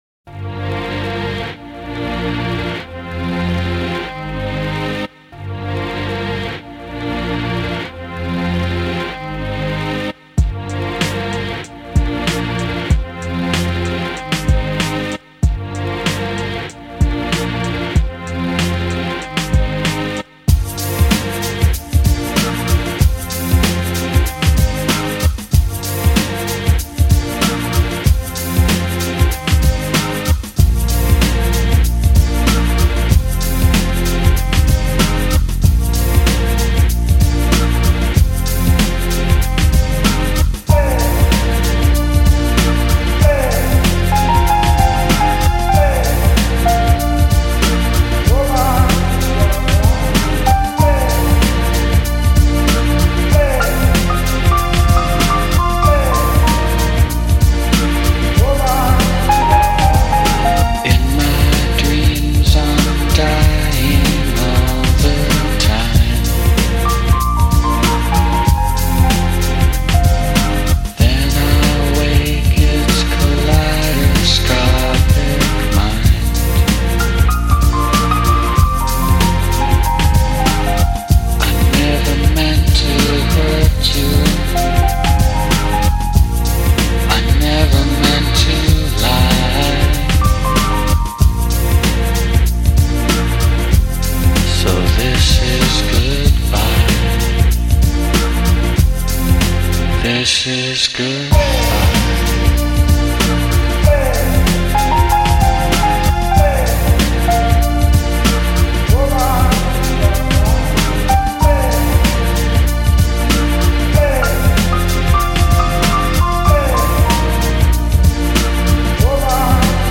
دانلود آهنگ لایت آرامش بخش